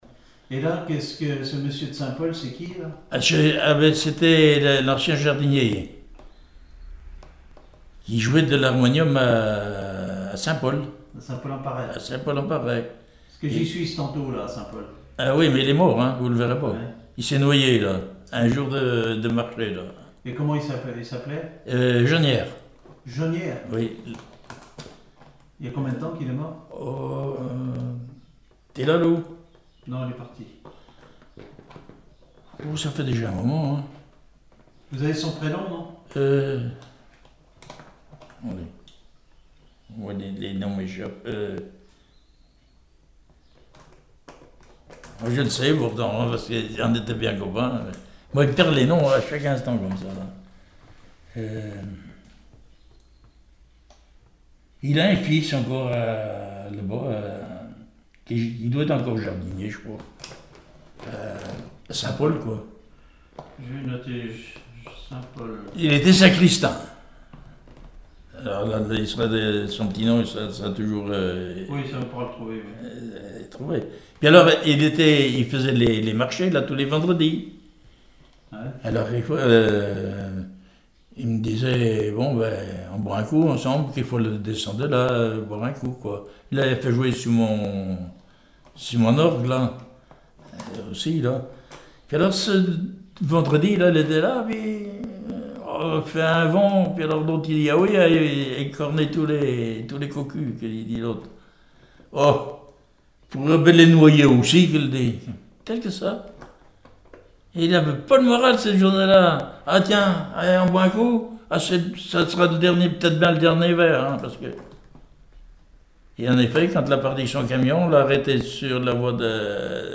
Enquête Arexcpo en Vendée
Catégorie Témoignage